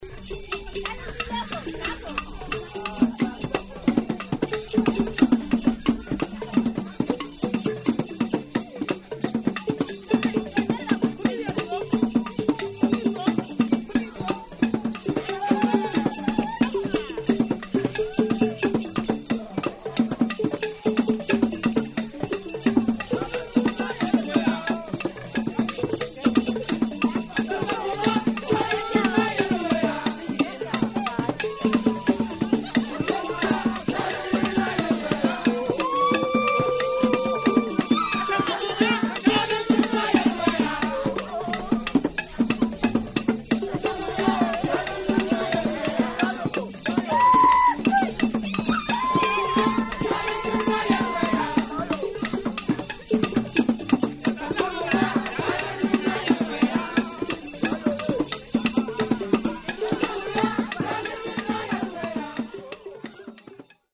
The jengsing is a xylophone with gourd resonators.
The jengsing is played with two wooden sticks, the heads of which are made of rubber. It is traditionally played in pairs in combination with the pendere drum at funerals.